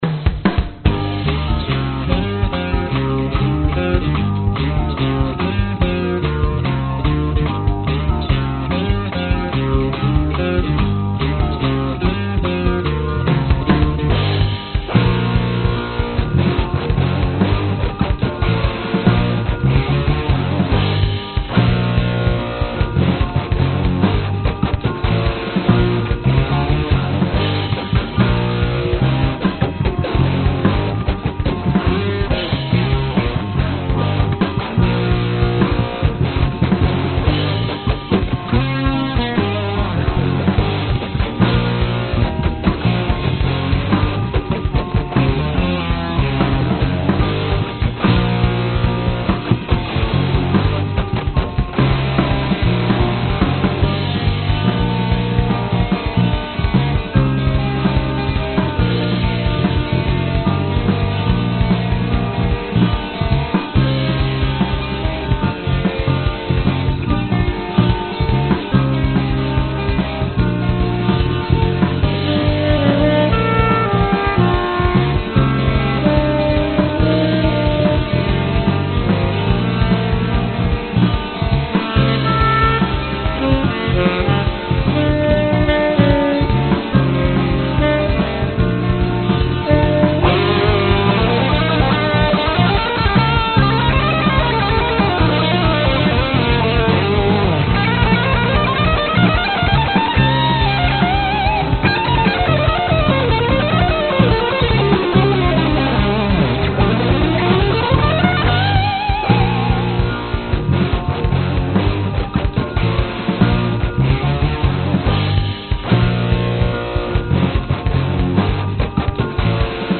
电吉他用Ibanez 6弦（调音DADGAD）和Vox ToneLab SE录制。吉他独奏用Ibanez 7弦琴录制。